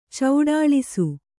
♪ cauḍāḷisu